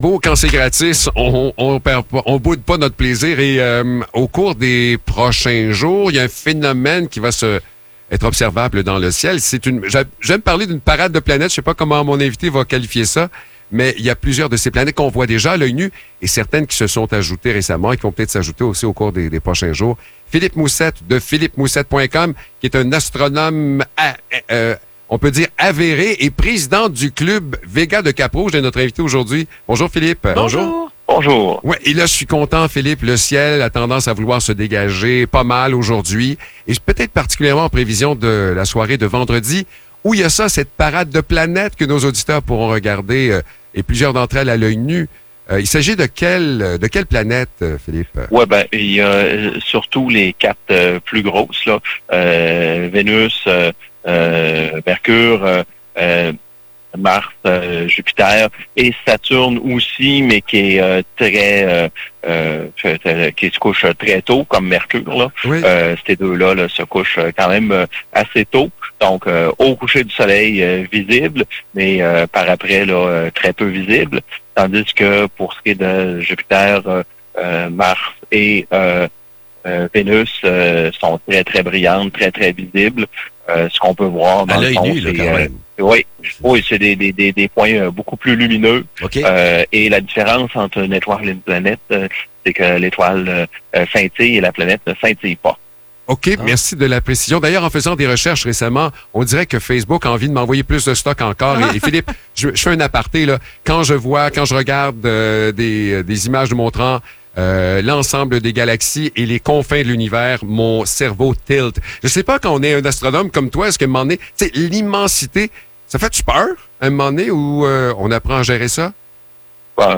Parade de planètes : Entrevue avec un astronome!